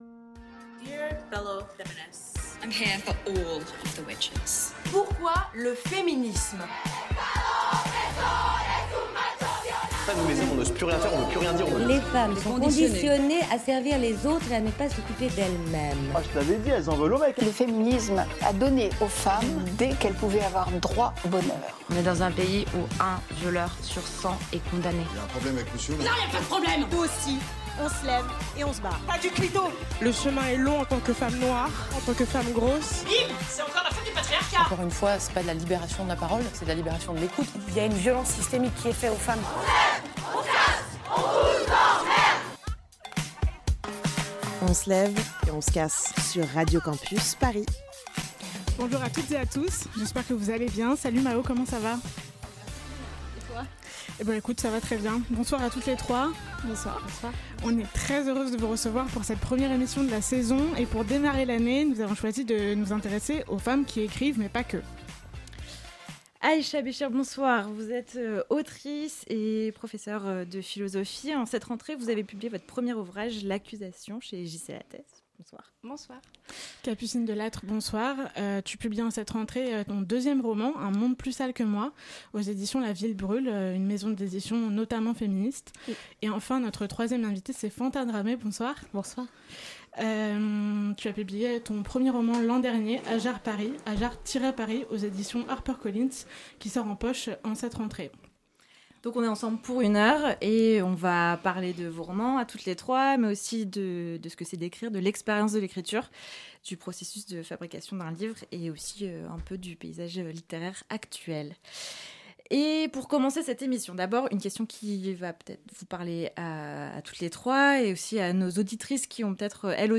Nos invitées nous partagent leurs motivations, leurs parcours, et leurs perspectives variées sur le processus de création littéraire.